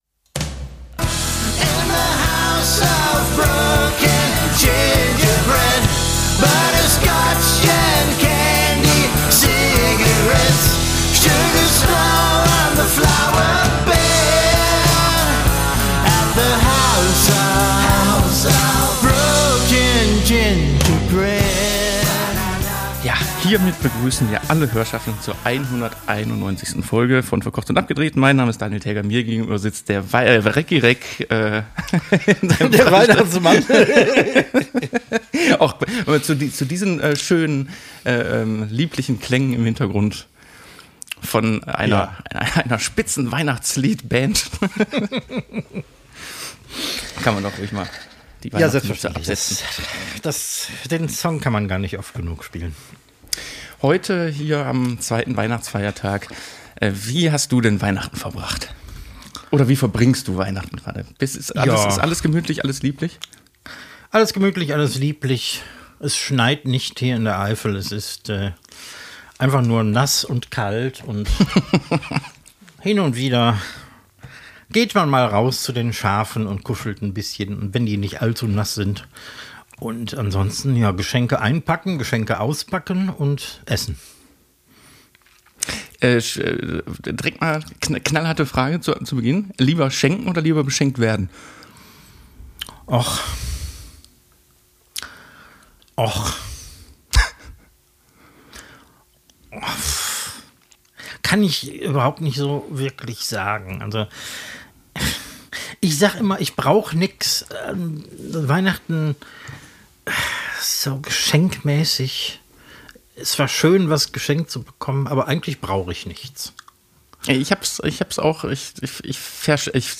Koch und Fernsehmann kommen einfach in euer Wohnzimmer gelaufen, setzen sich auf die Couch und beginnen sich zu unterhalten.